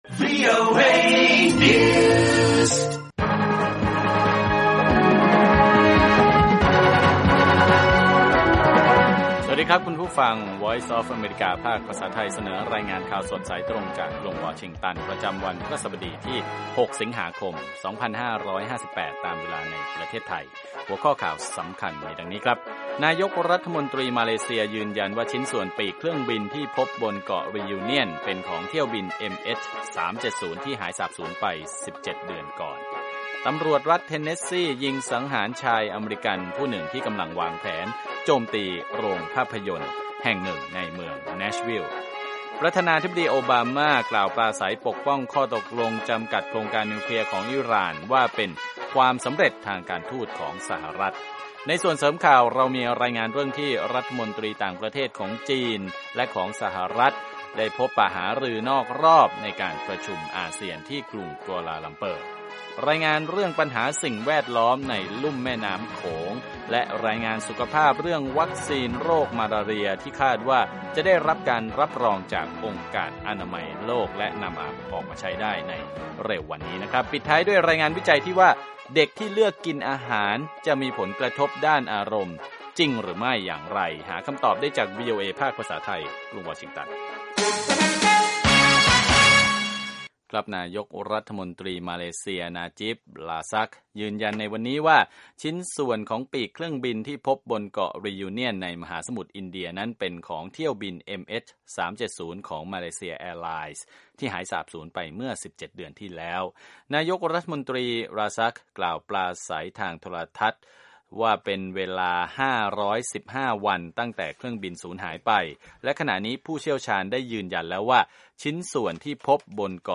ข่าวสดสายตรงจากวีโอเอ ภาคภาษาไทย 8:30–9:00 น. วันพฤหัสบดีที่ 6 ส.ค 2558